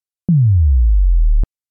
Now we want to shape the pitch so that it bends by itself when we hit the key.
Because the pitch has to go down, we know the modulation amount has to be negative.